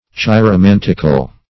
Meaning of chiromantical. chiromantical synonyms, pronunciation, spelling and more from Free Dictionary.
Search Result for " chiromantical" : The Collaborative International Dictionary of English v.0.48: Chiromantic \Chi`ro*man"tic\, Chiromantical \Chi`ro*man"tic*al\a. Of or pertaining to chiromancy.